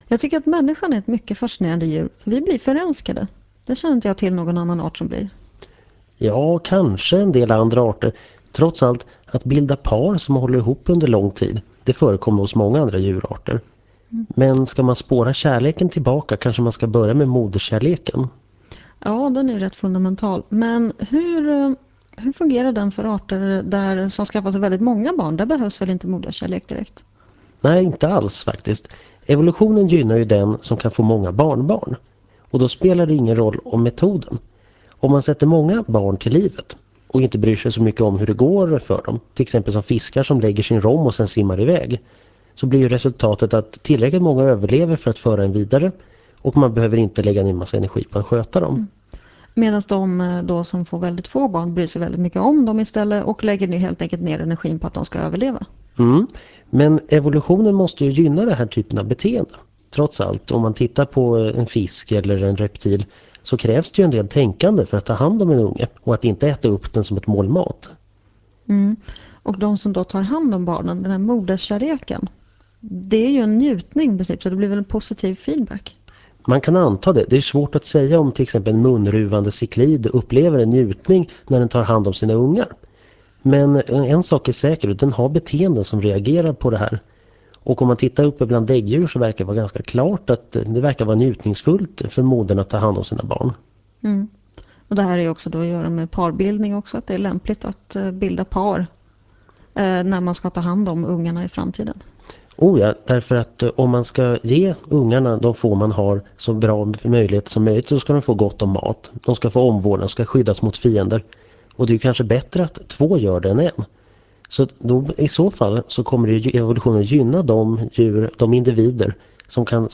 Föredraget handlar om biologi och sänds i Etervåg.